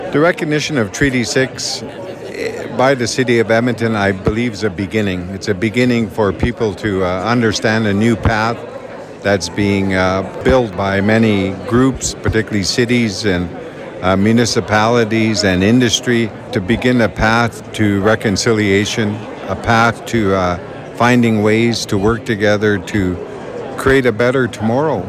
The City of Edmonton and Confederacy of Treaty Six Nations gathered together for a flag raising ceremony at City Hall to commemorate Treaty Six Recognition Day in Edmonton.
Chief George Arcand Jr of Alexander First Nation says the recognition of Treaty Six by the City of Edmonton is a beginning of a new path of understanding and Reconciliation to work together to create a better tomorrow.